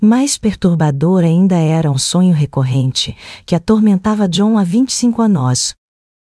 Áudios Gerados - Genesis TTS